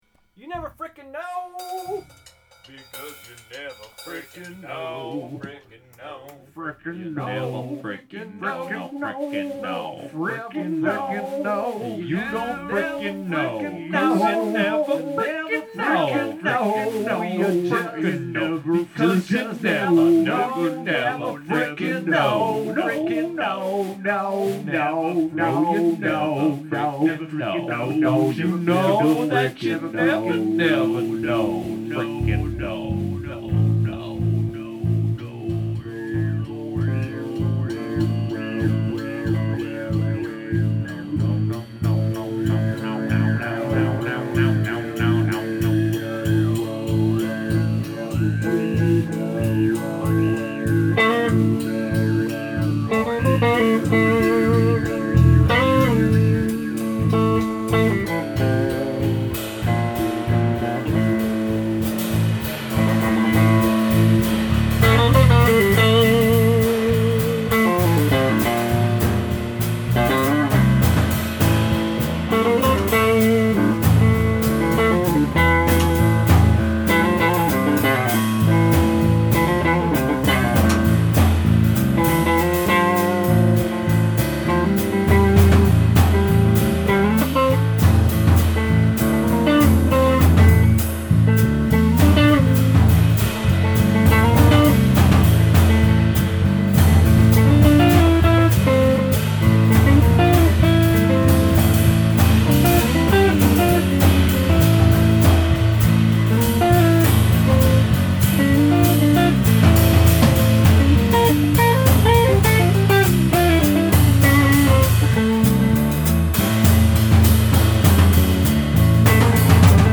The 'Floating-Roster Ever-Expanding Live-Album Blog'
Warmup Jam (10 megs) Never Frickin' Know (16 megs) Here are a couple of fully improvised movements from this past Monday's 'Map of the Woulds' rehearsal.